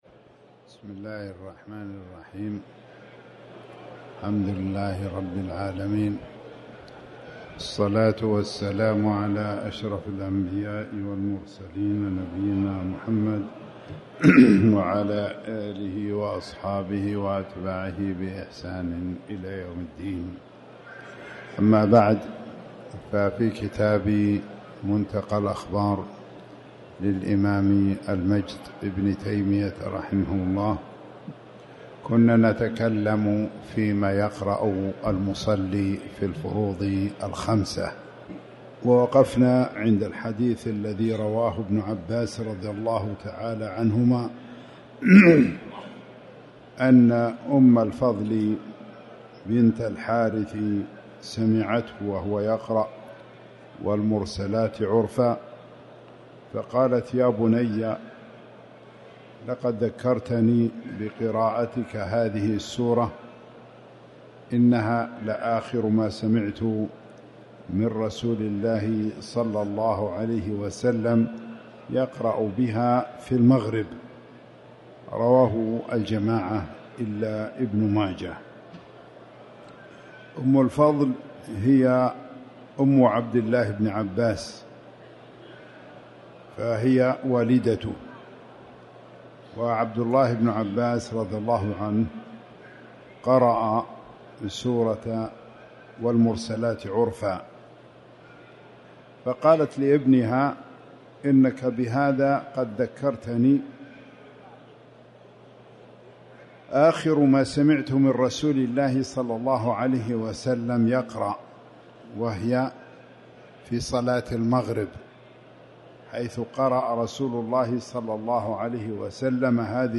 تاريخ النشر ١٥ شوال ١٤٤٠ هـ المكان: المسجد الحرام الشيخ